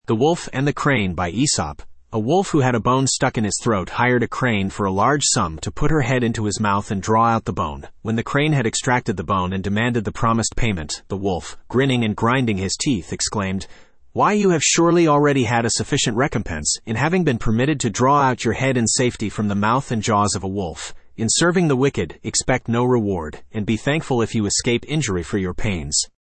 Studio (Male)